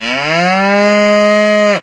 Cow.ogg